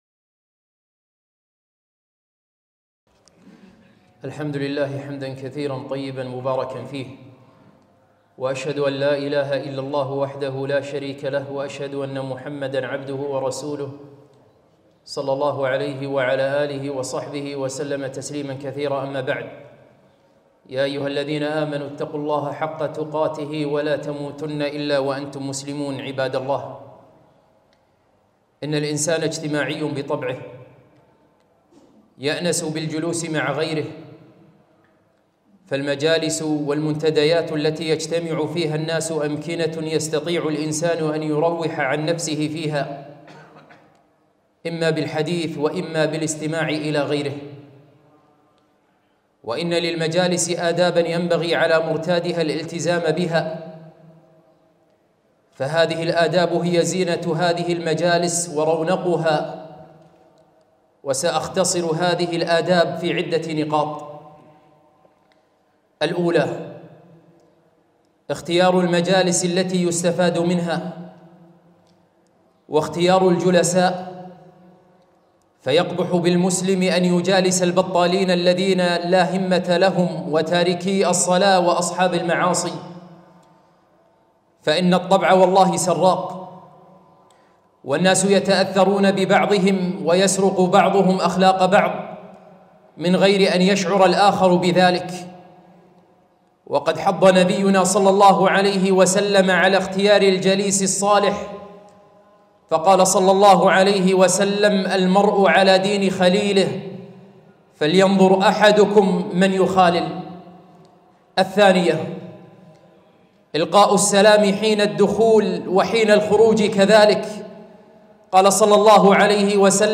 خطبة - من آداب المجالس